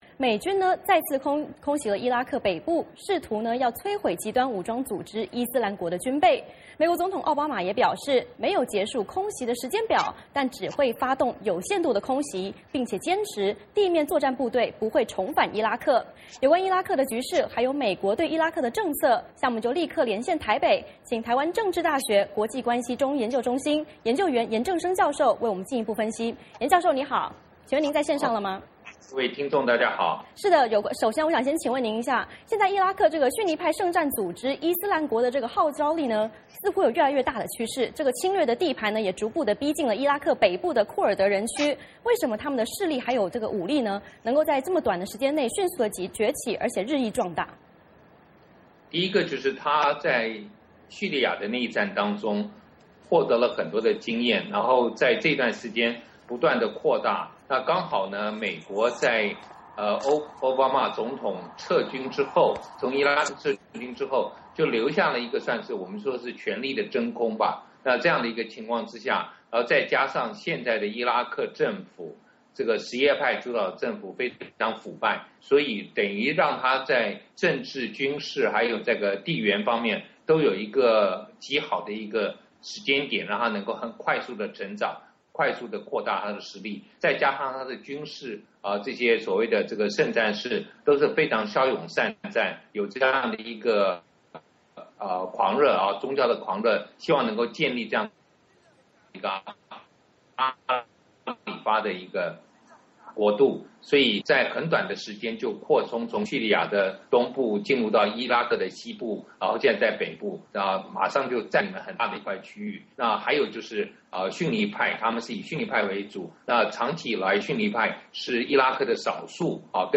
VOA连线：专家分析：伊拉克极端分子快速崛起原因